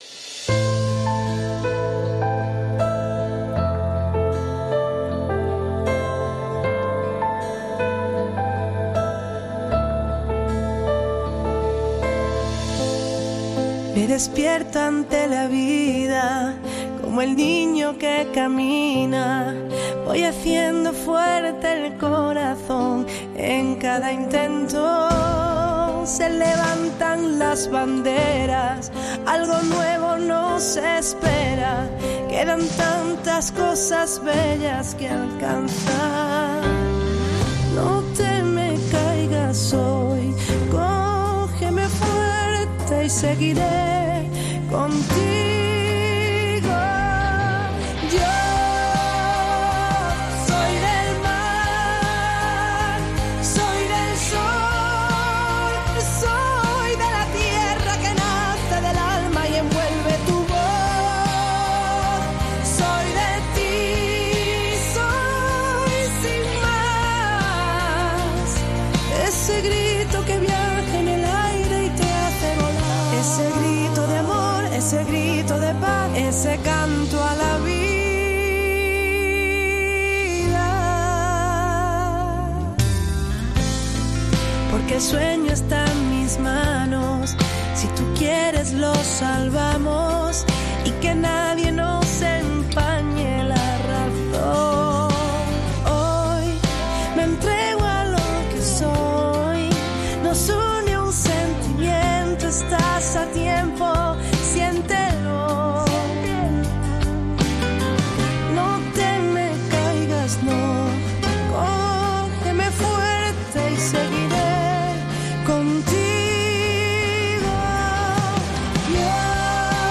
En estos días de confinamiento estamos entrando en las casas también de amigos como Vanesa Martin. Justo Molinero ha estado charlando con Vanesa Martín de muchas cosas